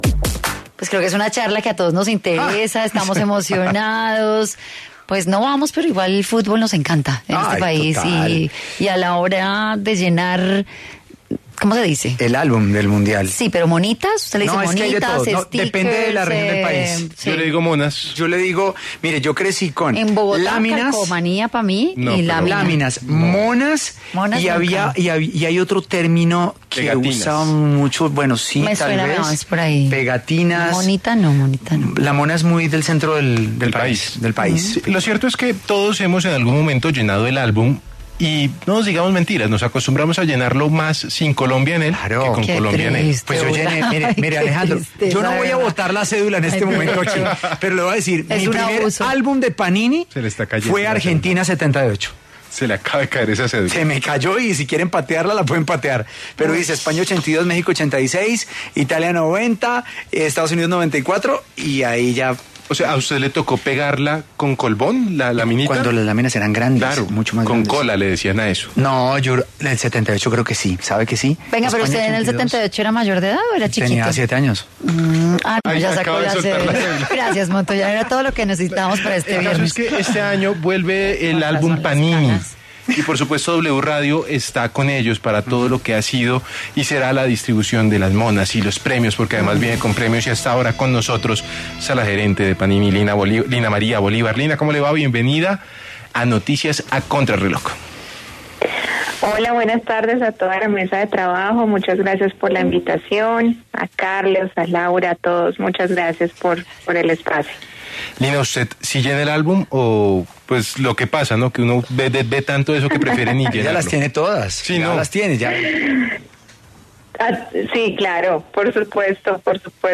dialogó con Contrarreloj de W Radio sobre el lanzamiento del álbum que apasiona a coleccionistas y amantes del fútbol.